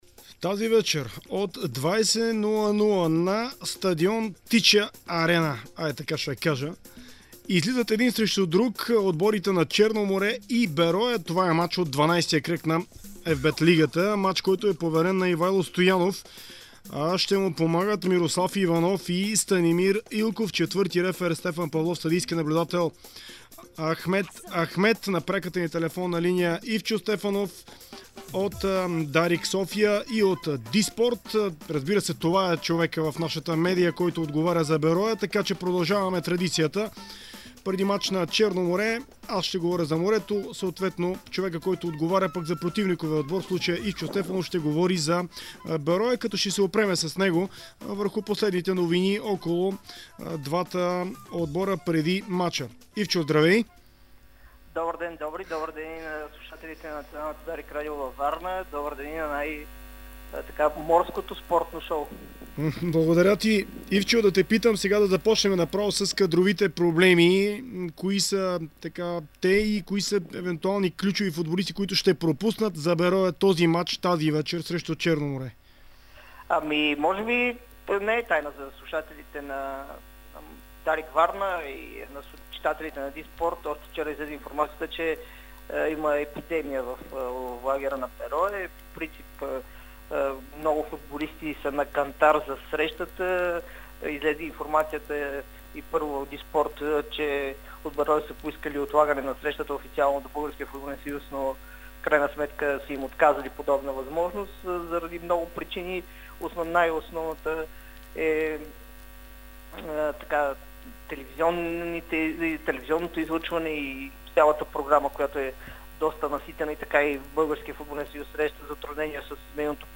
Чуйте в аудио файла какво споделиха двамата в спортното предаване на "Дарик Варна".